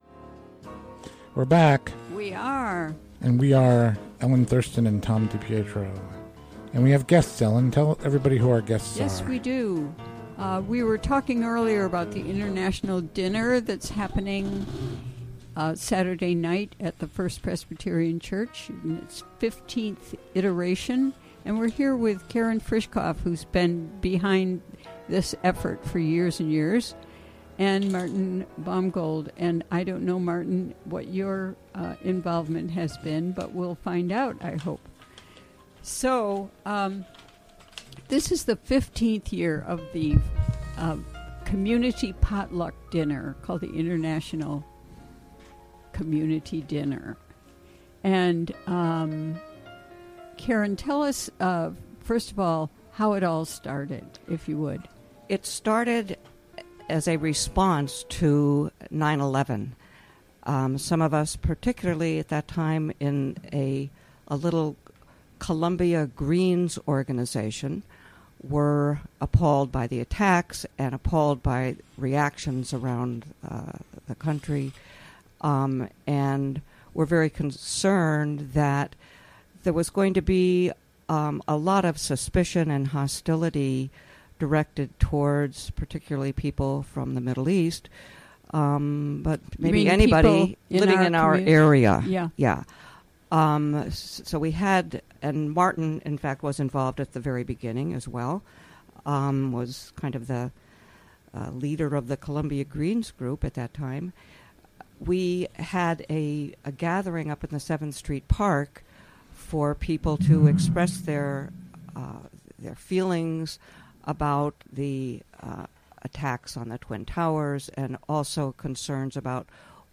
Interview from the WGXC Afternoon Show April 28.